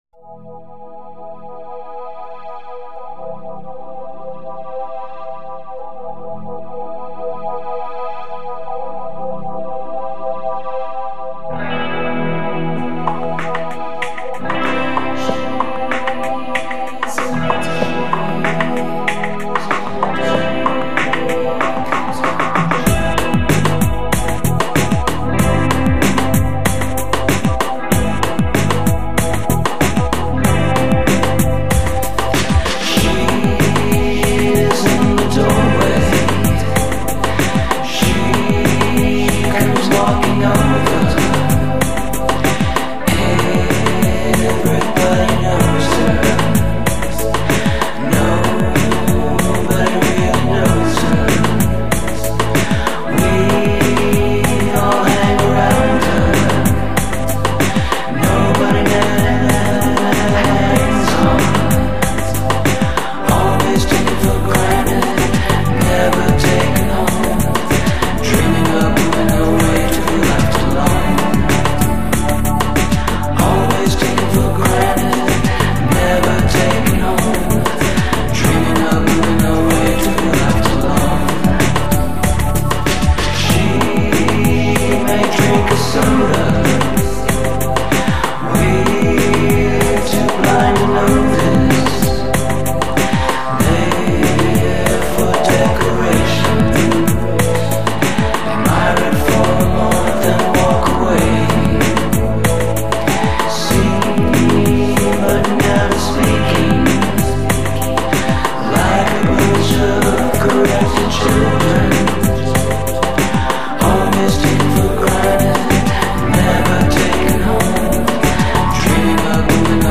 Recapturing AM radio in pop songs with lush arrangements.